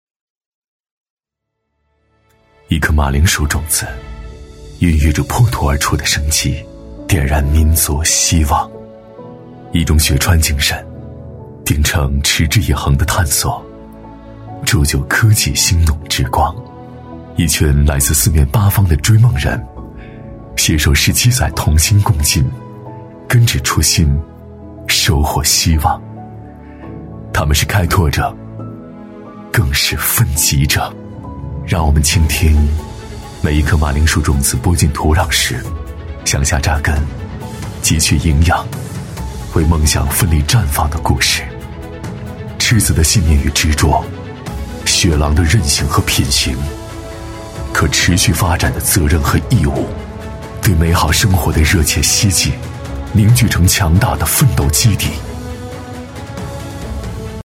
男B34-颗马铃薯种子-低沉磁性
男B3-磁性稳重 质感磁性
男B34-颗马铃薯种子-低沉磁性.mp3